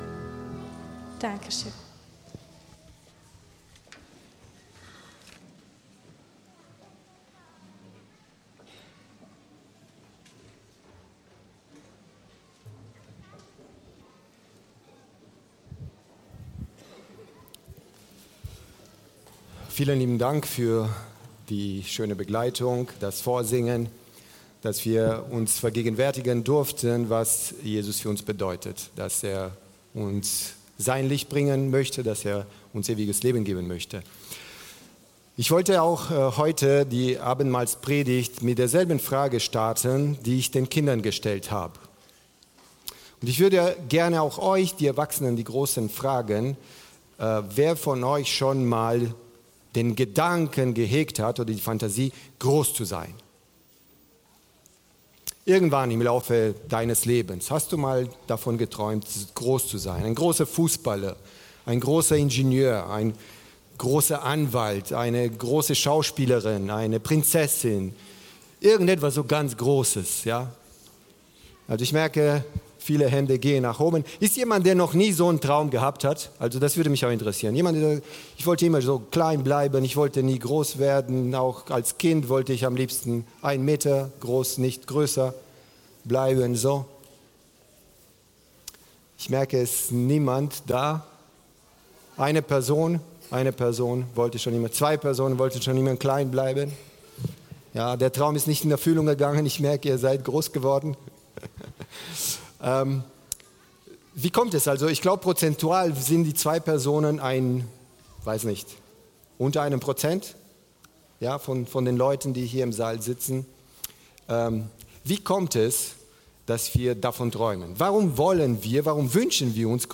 Mitschnitt vom 08.11.2025 zum Thema „Abendmahl"